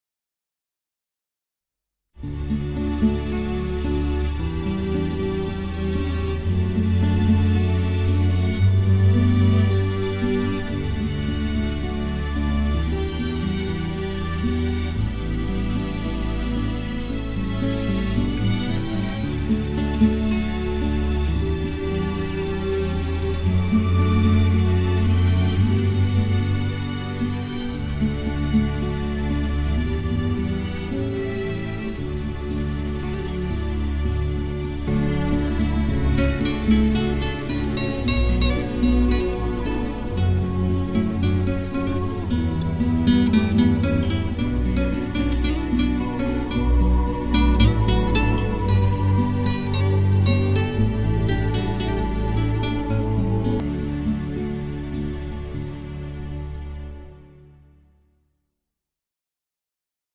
Méditation musicale :